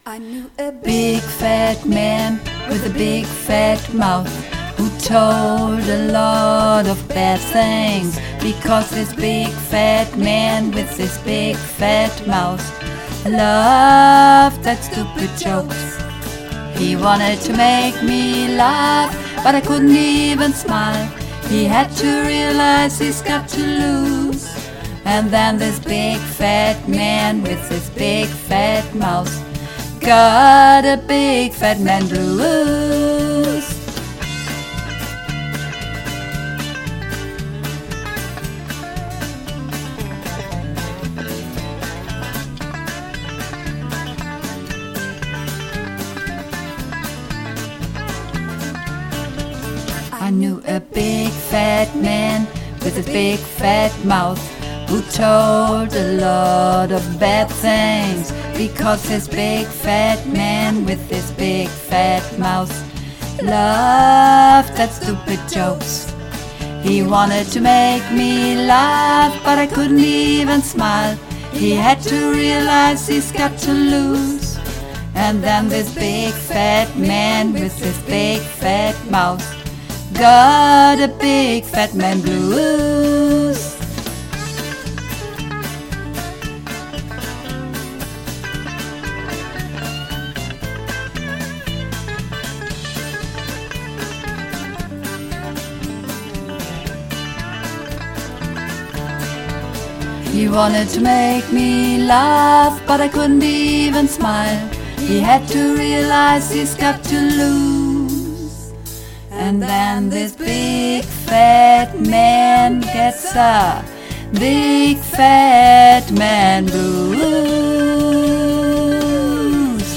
Übungsaufnahmen - Big Fat Man